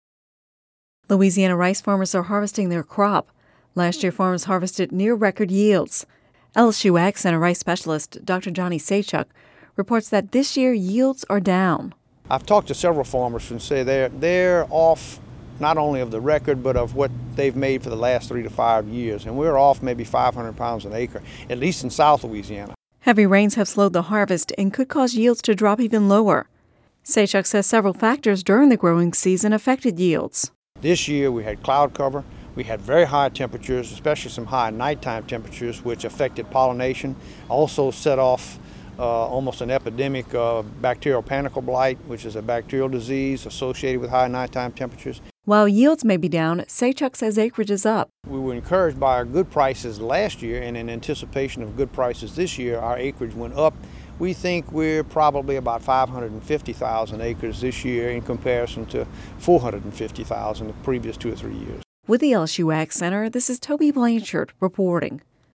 (Radio News 08/30/10) Louisiana rice farmers are harvesting their crop.